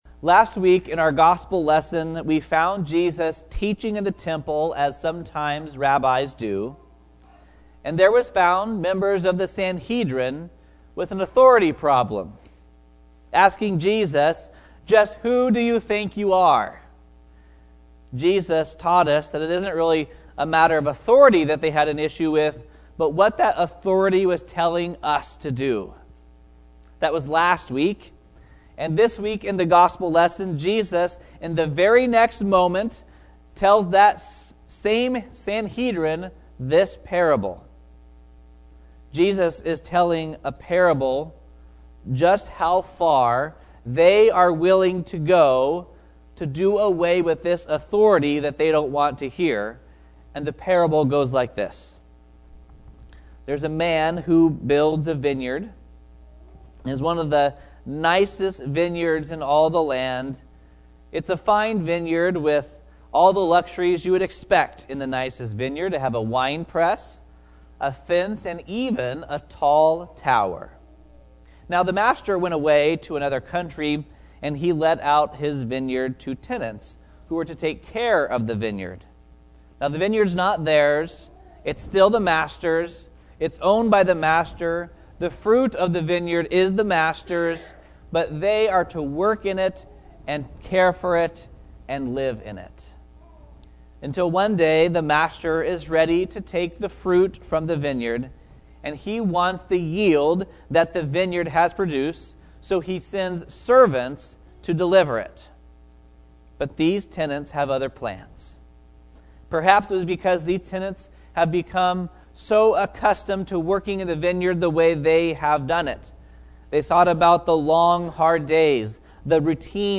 Ladies Bible Study: Jeremiah 23:20-32